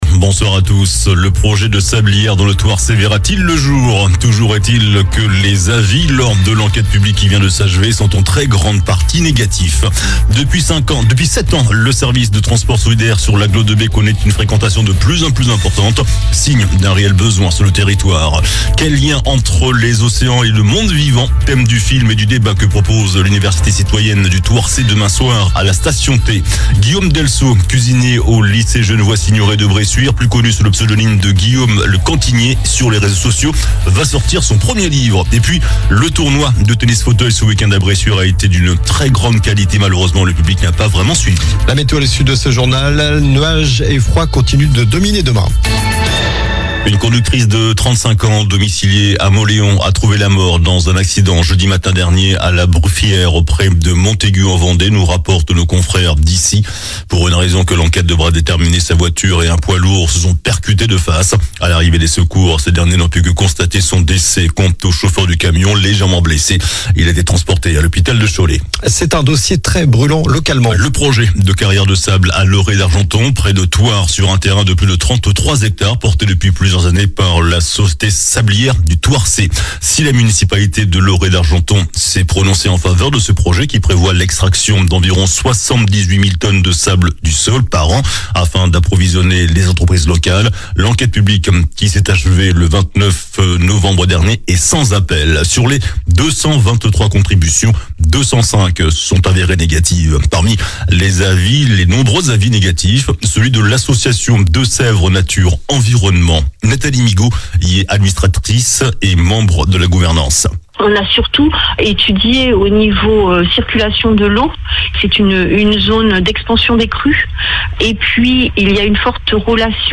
JOURNAL DU LUNDI 09 DECEMBRE ( SOIR )